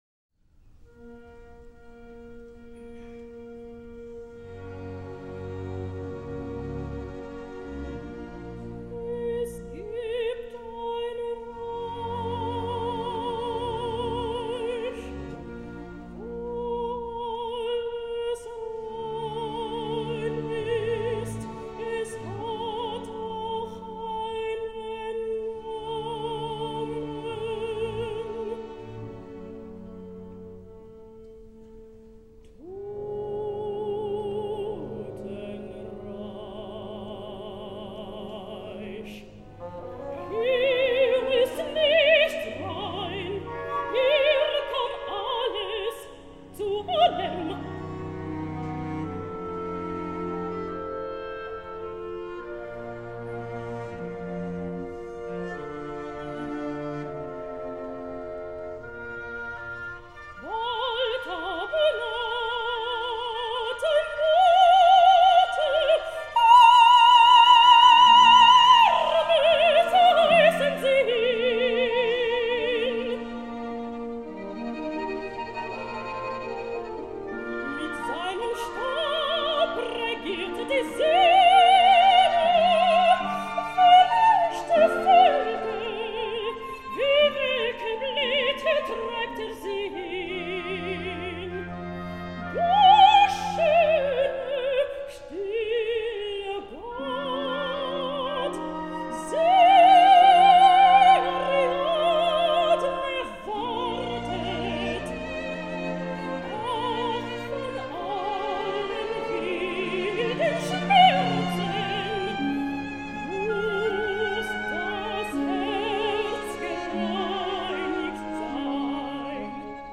Michèle Crider, Soprano. Richard Strauss: Es gibt ein Reich (Ariadne’s aria).
Orchestre de la Suisse Romande. Armin Jordan, conductor.